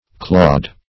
Clawed \Clawed\ (kl[add]d), a.